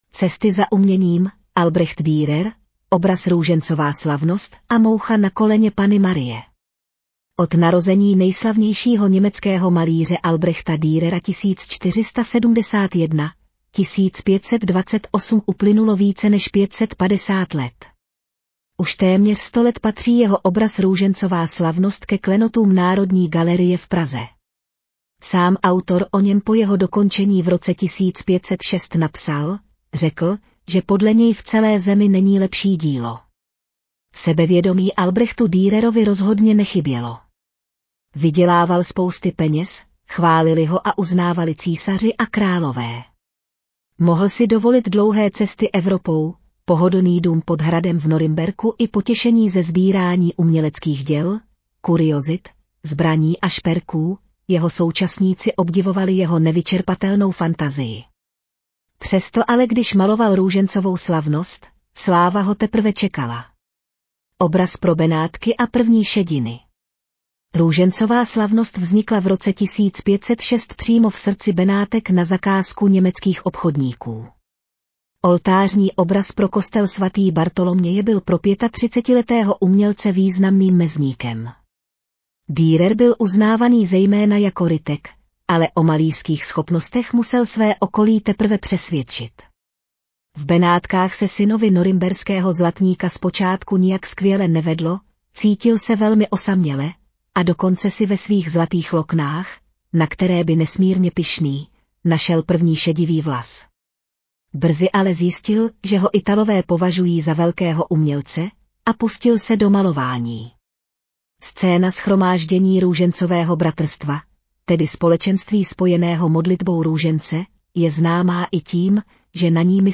Audio verze článku Cesty za uměním: Albrecht Dürer, obraz Růžencová slavnost a moucha na koleně Panny Marie